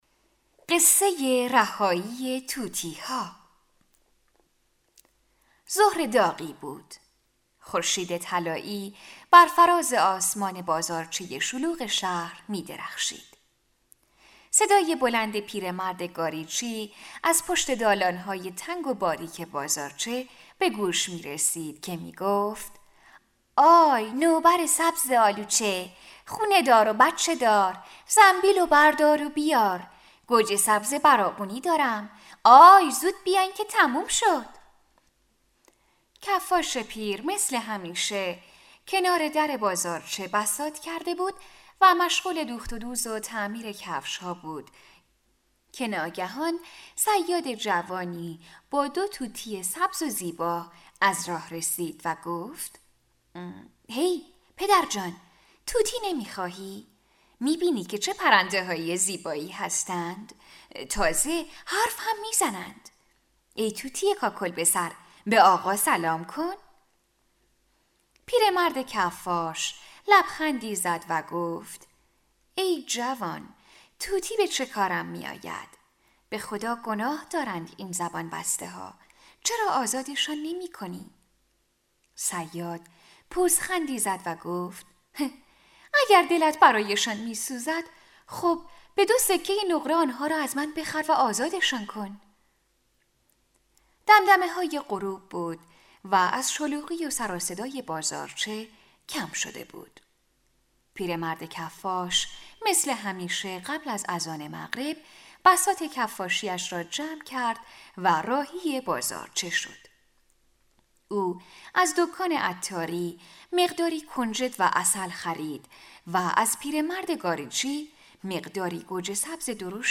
قصه های کودکانه